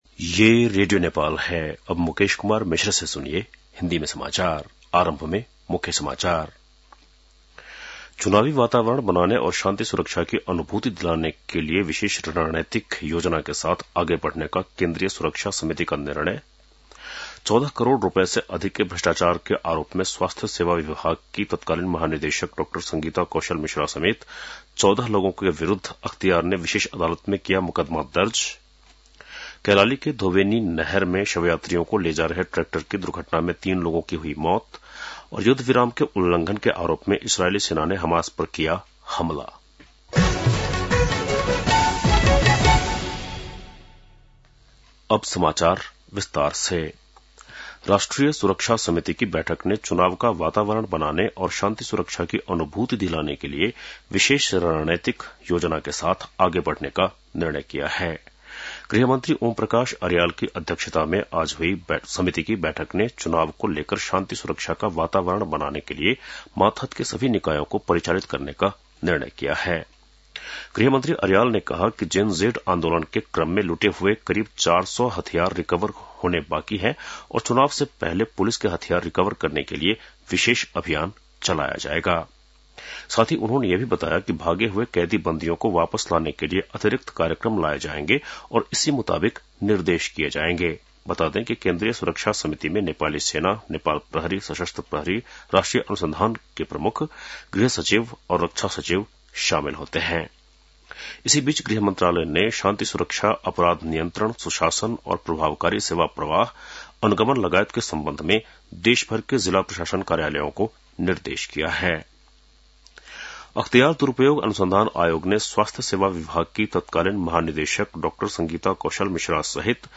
बेलुकी १० बजेको हिन्दी समाचार : २ कार्तिक , २०८२
10-pm-hindi-news-.mp3